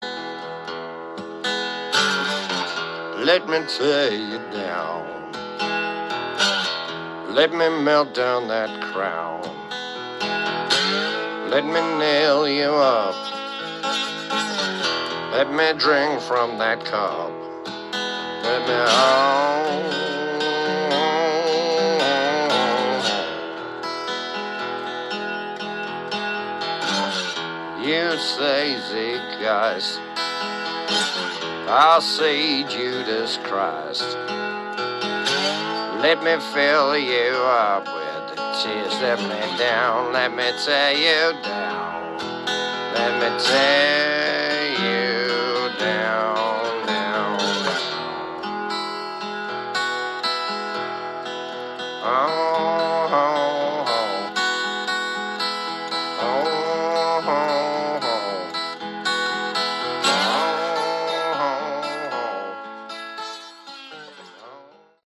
Category: Hard Rock
acoustic demo